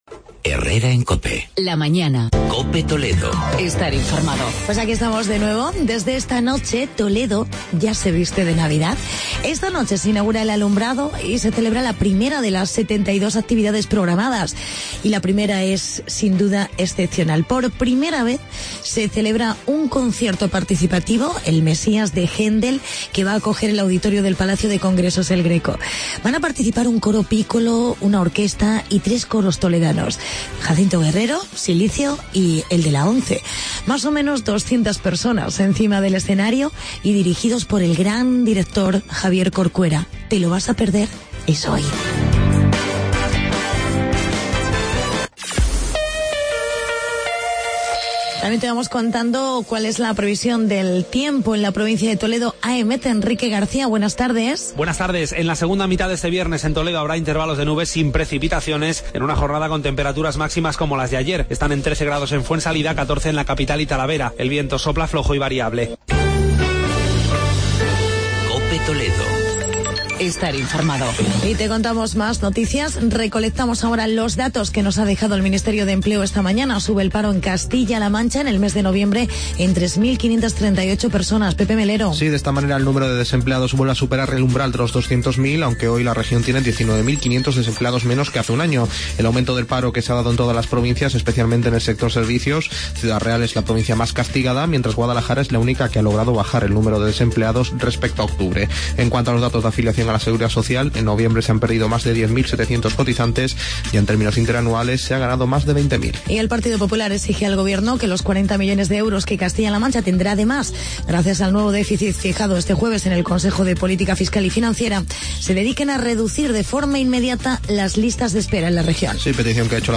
Actualidad y reportaje sobre Viviendas para Mayores.